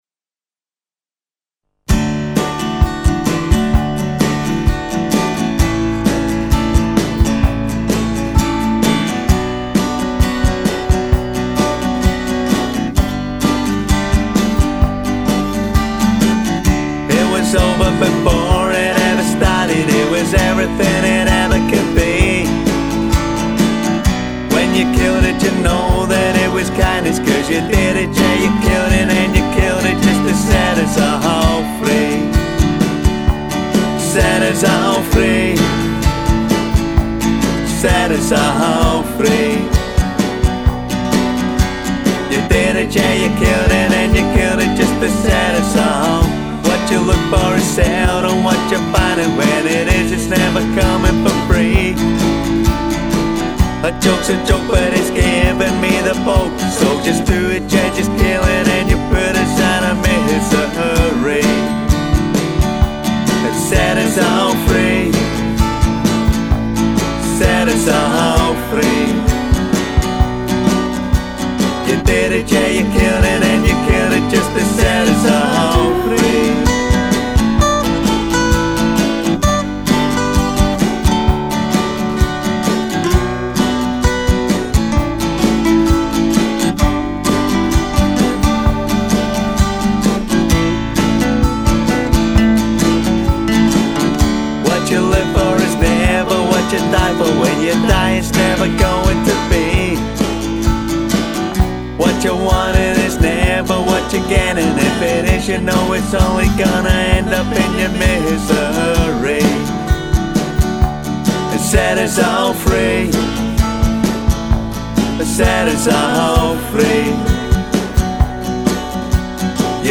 • Genre: Folk / Pop